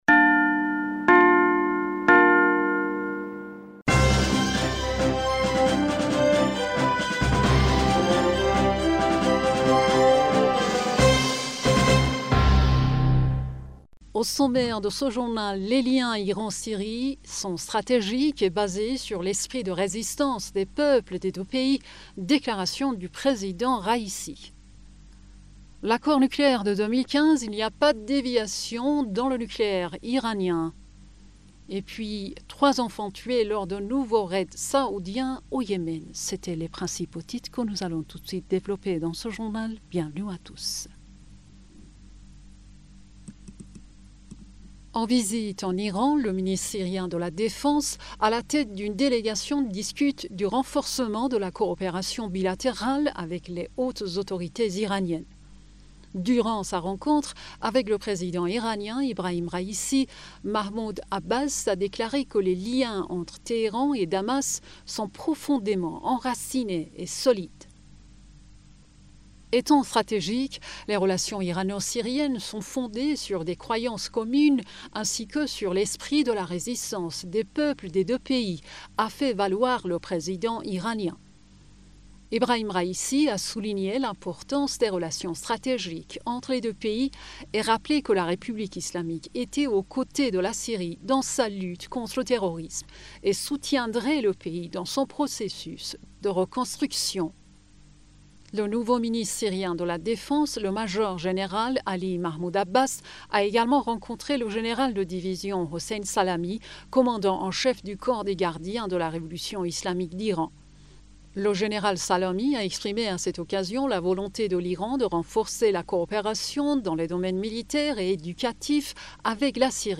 Bulletin d'information du 25 Janvier